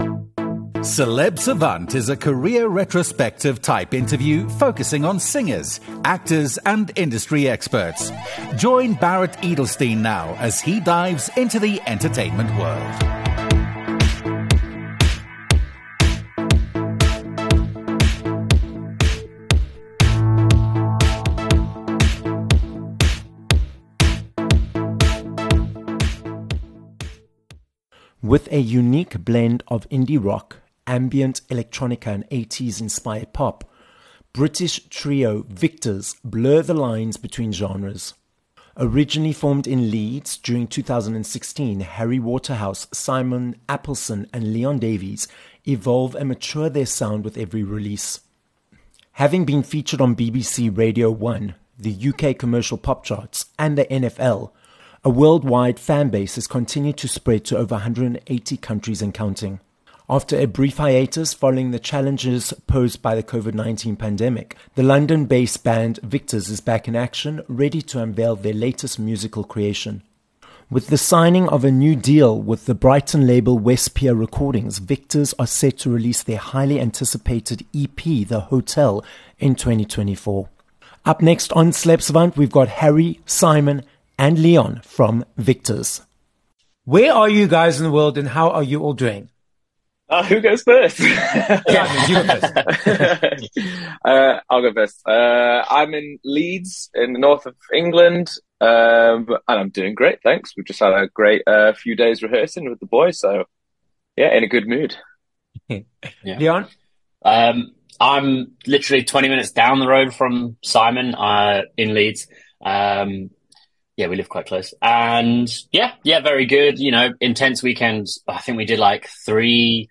26 May Interview with Victors